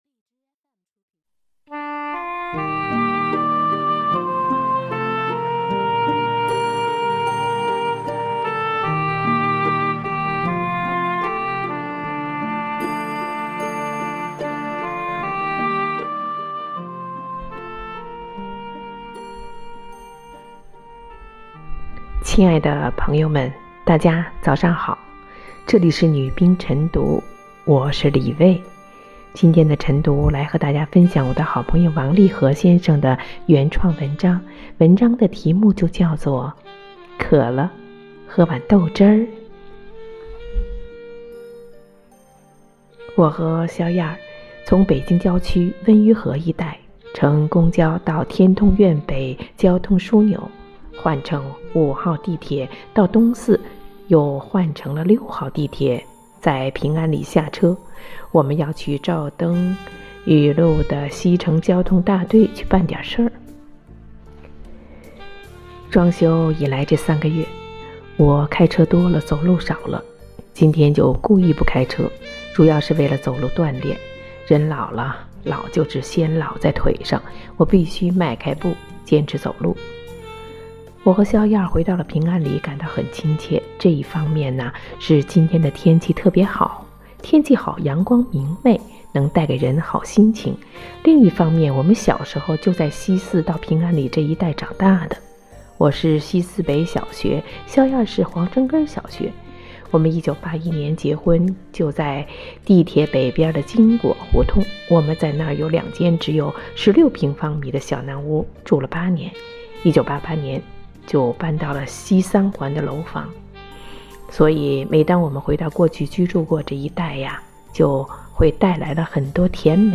每日《女兵诵读》